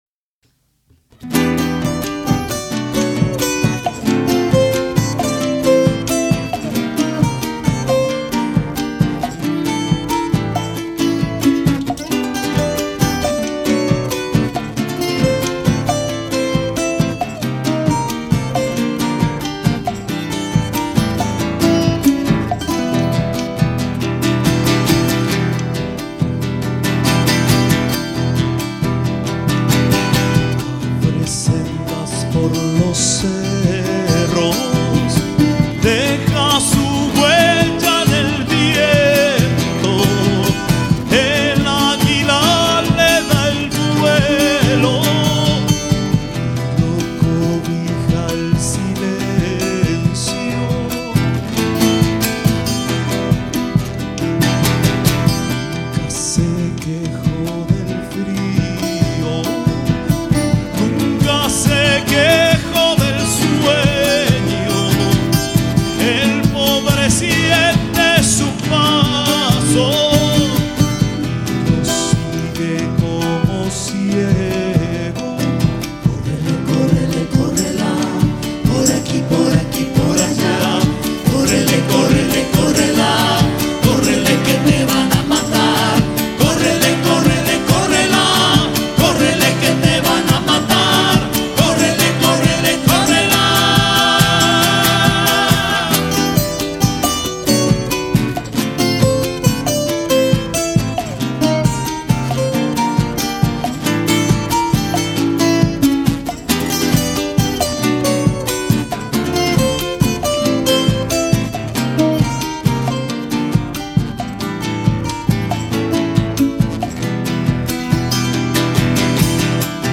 voce, chitarra, tiple, charango
sax tenore e soprano. flauti
Registrato a Milano - CSOA Leoncavallo il 7 marzo 2000